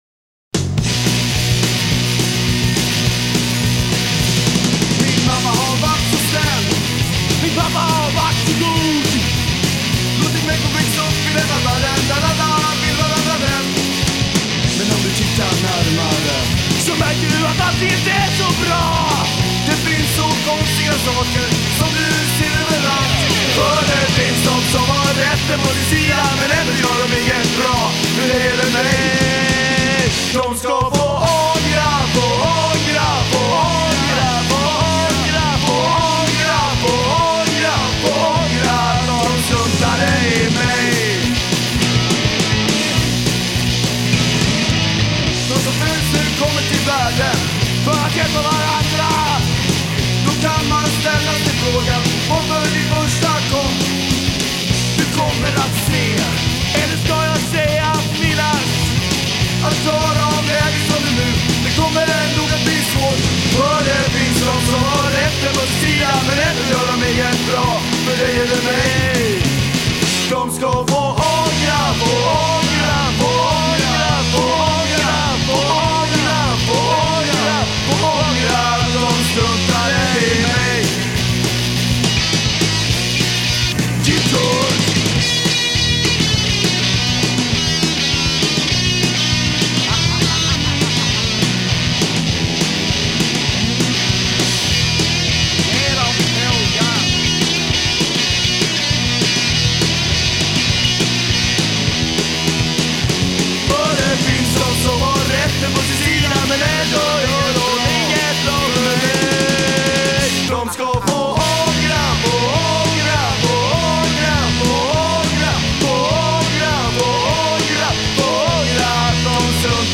punkband